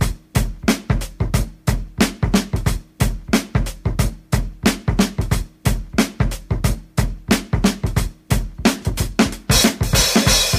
• 91 Bpm Drum Loop Sample E Key.wav
Free breakbeat sample - kick tuned to the E note. Loudest frequency: 2155Hz
91-bpm-drum-loop-sample-e-key-Fix.wav